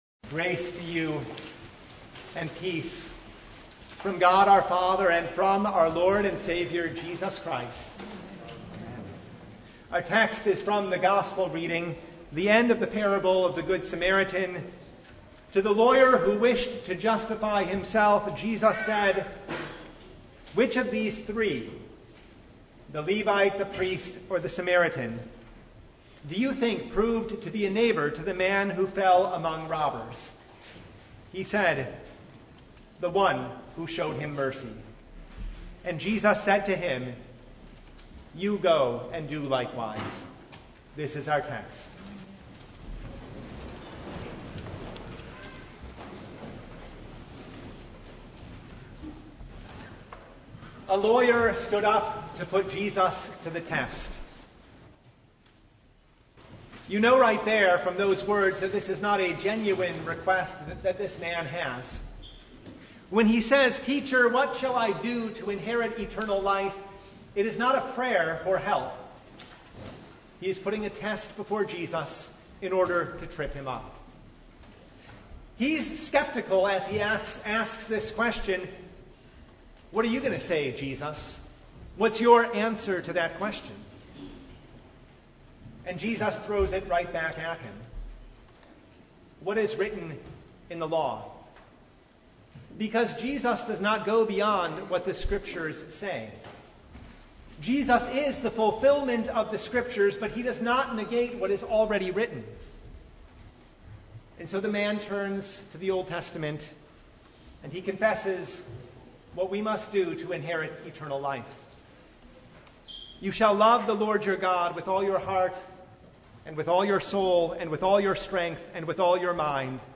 Luke 10:25–37 Service Type: Sunday In the Parable of the Good Samaritan